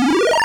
fsDOS_teleporterActivated.wav